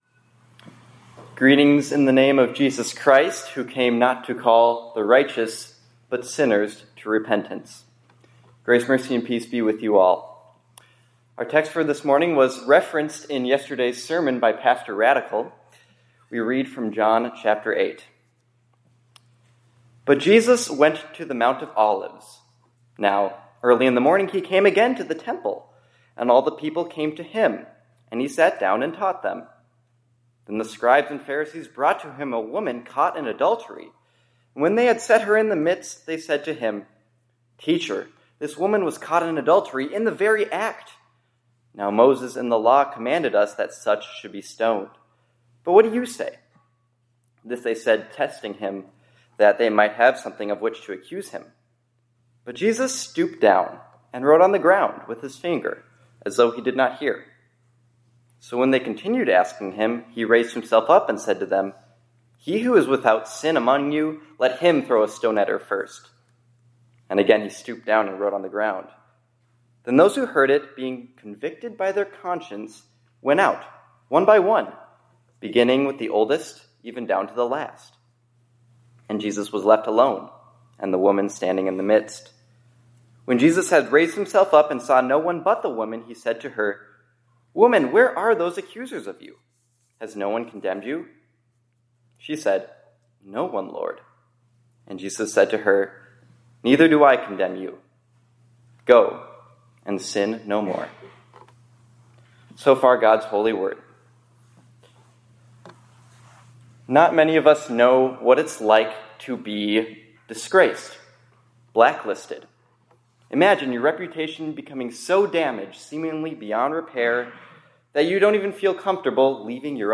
2026-03-02 ILC Chapel — Only the Sinless One Has the Right to Throw Stones